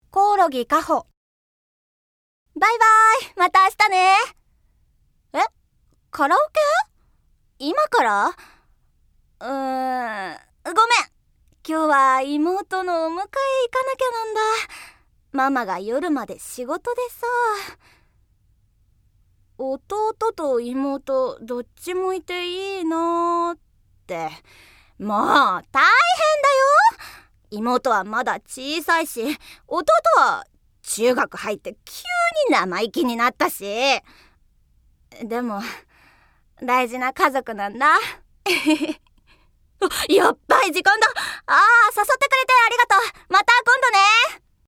◆快活な女子高生◆